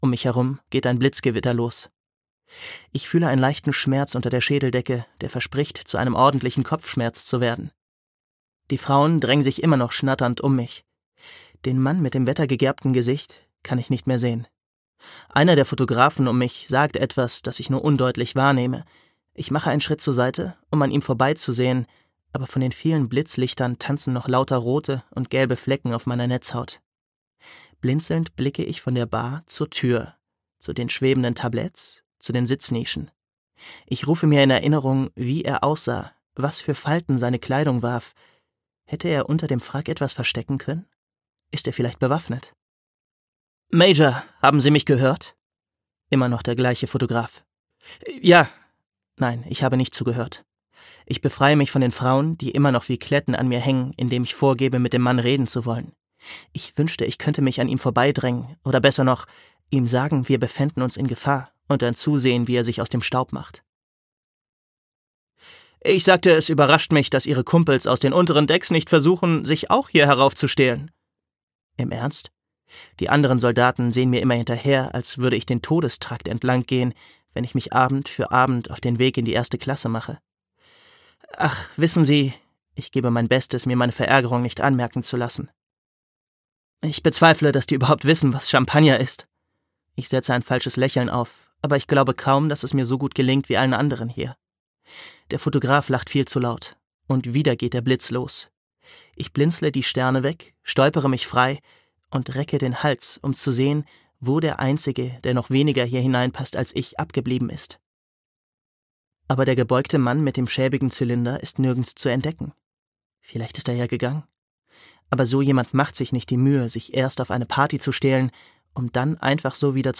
These Broken Stars. Lilac und Tarver - Amie Kaufman - Hörbuch